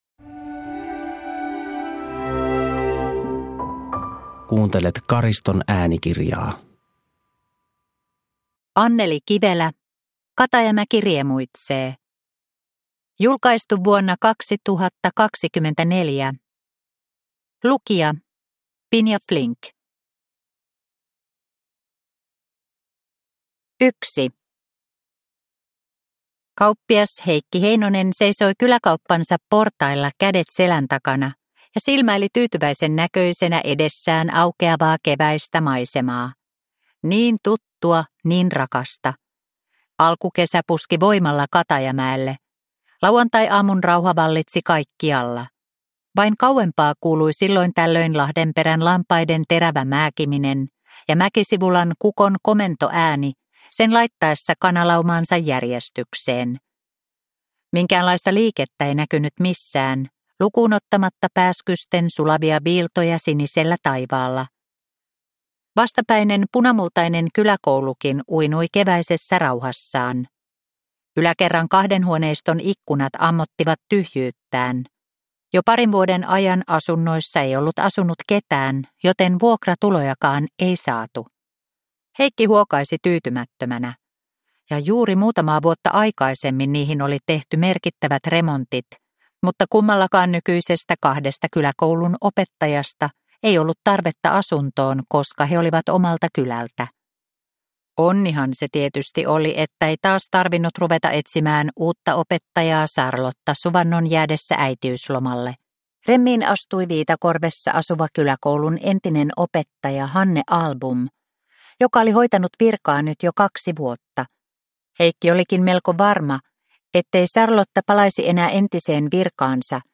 Katajamäki riemuitsee (ljudbok) av Anneli Kivelä